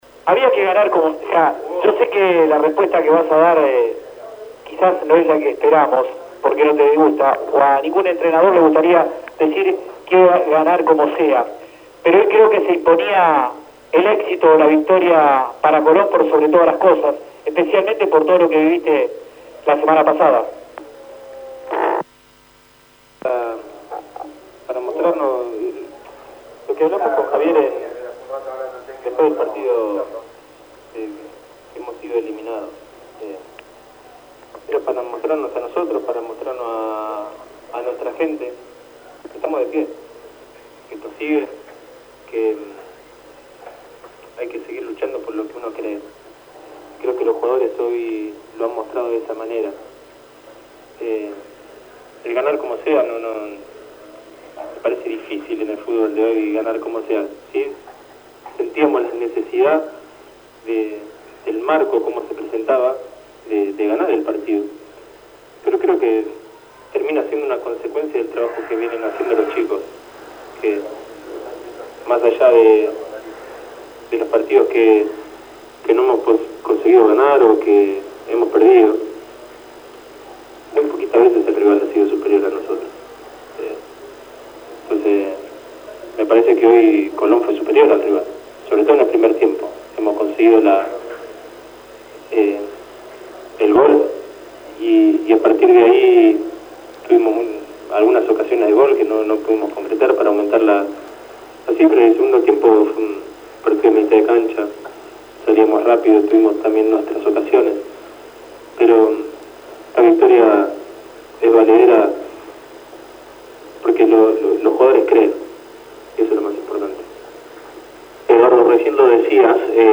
• Conferencia de prensa